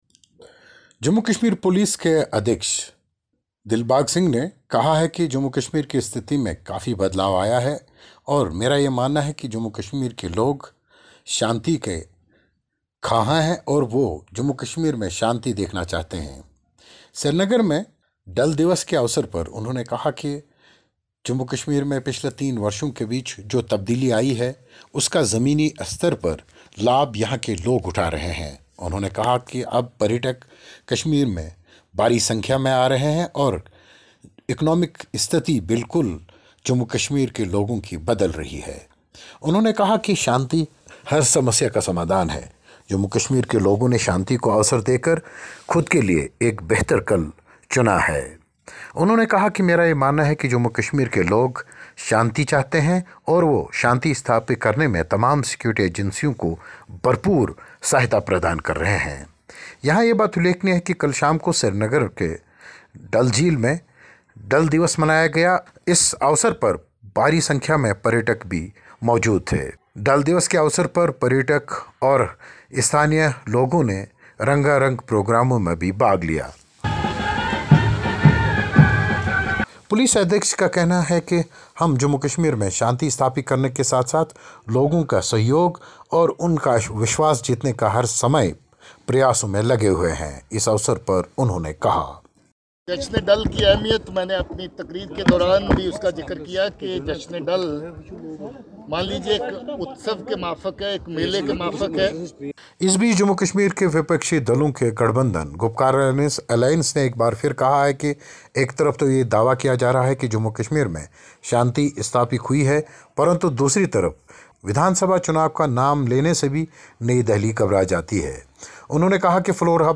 जम्मू-कश्मीर में सब शांति है, रिपोर्ट